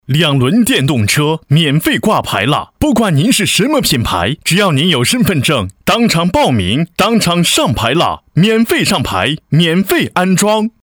男42号配音师
4年从业经验，声音可活泼、可浑厚、可大气。
代表作品 Nice voices 促销 飞碟说 专题片 宣传片 促销-男42-两轮电动车.mp3 复制链接 下载 促销-男42-618广告.mp3 复制链接 下载